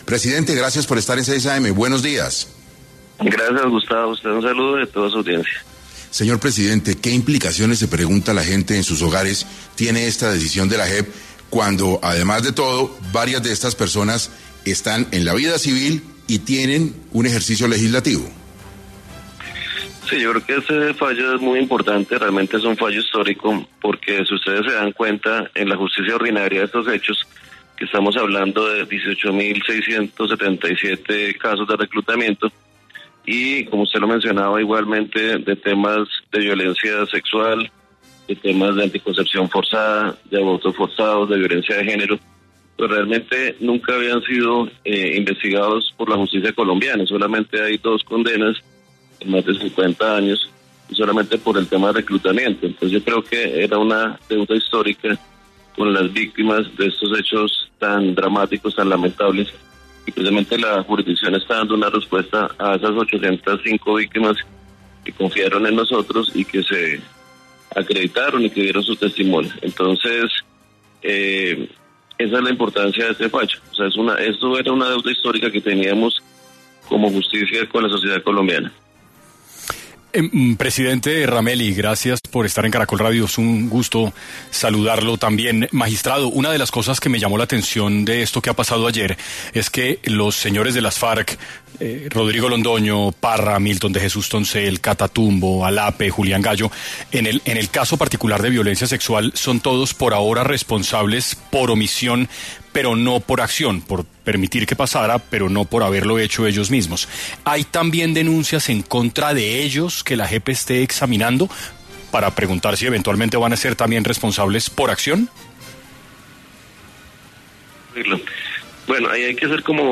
En 6AM de Caracol Radio estuvo Alejandro Ramelli, Presidente de la JEP, quien habló sobre la imputación que hizó la entidad a seis ex integrantes de las Farc por los crímenes de guerra que hicieron con el reclutamiento de 18.677 niños.